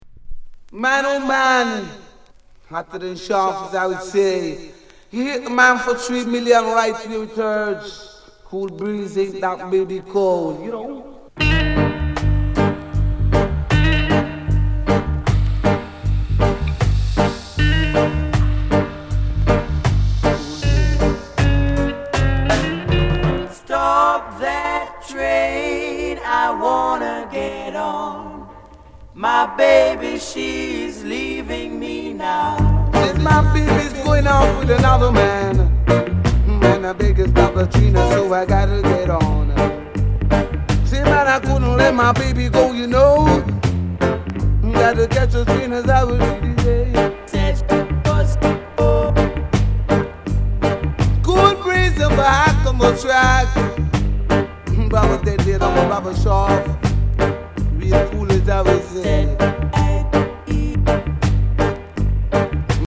DEE JAY CUT